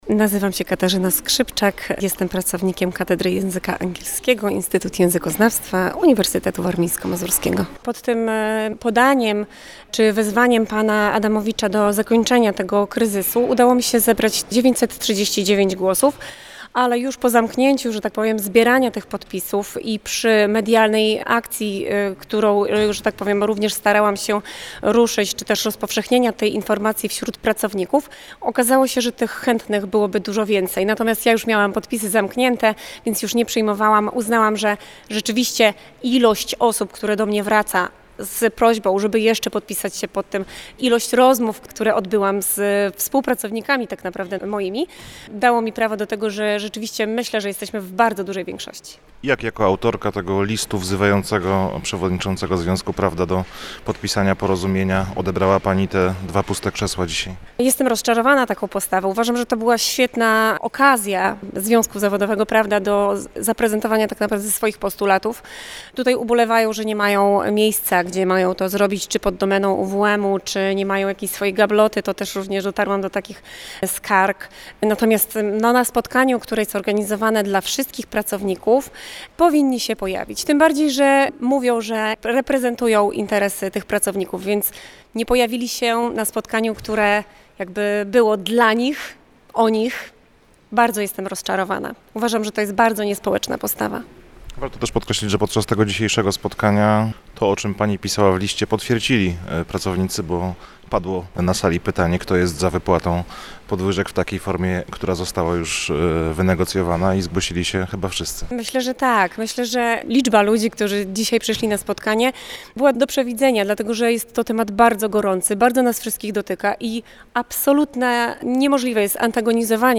14 listopada w Centrum Konferencyjnym Uniwersytetu Warmińsko-Mazurskiego w Olsztynie odbyło się spotkanie rektora z pracownikami i przedstawicielami działających na uczelni związków zawodowych.